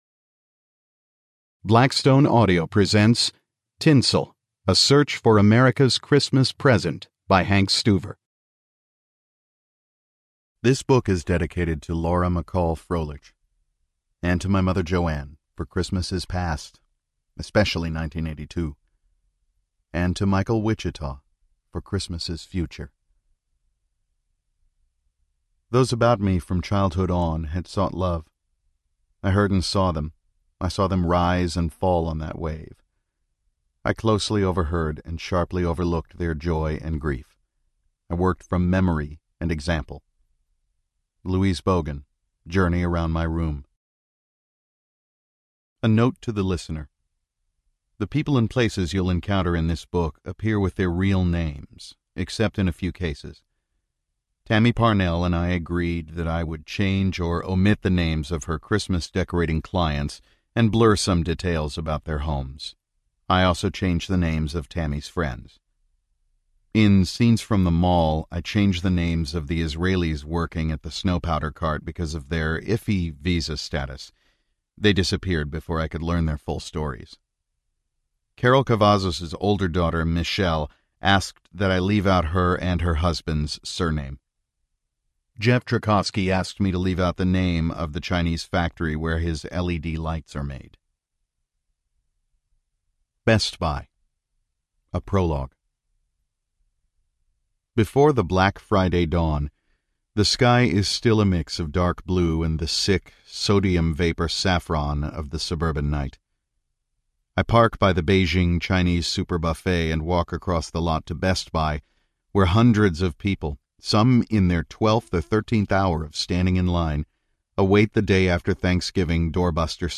Tinsel Audiobook
10.3 Hrs. – Unabridged